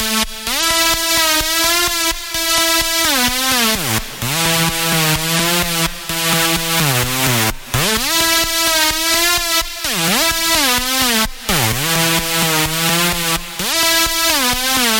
音乐部分 " 45秒鼓循环 160
描述：一个合成的、突变的鼓点以160 bpm的速度循环播放
Tag: 160 出问题 毛刺 滚筒机 BPM 击败